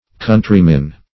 Countrymen - definition of Countrymen - synonyms, pronunciation, spelling from Free Dictionary
Countryman \Coun"try*man\ (k[u^]n"tr[i^]-man), n.; pl.
Countrymen (-men).